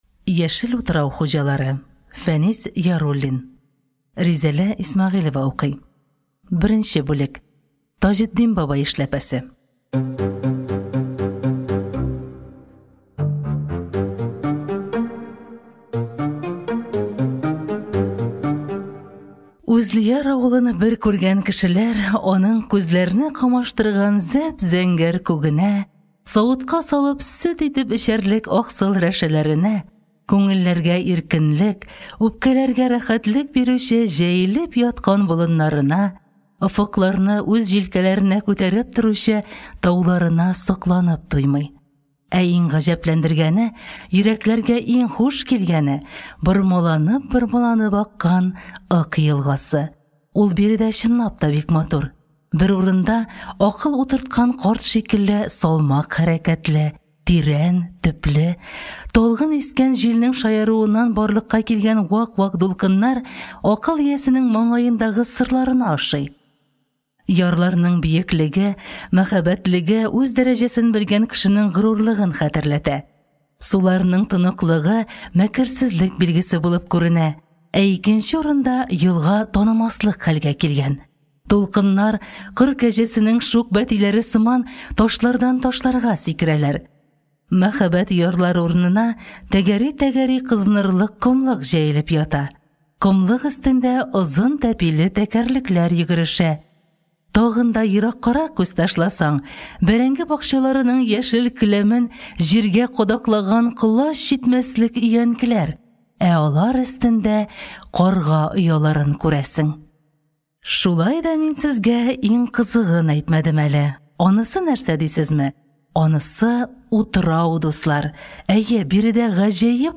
Аудиокнига Яшел үтраү хуҗалары | Библиотека аудиокниг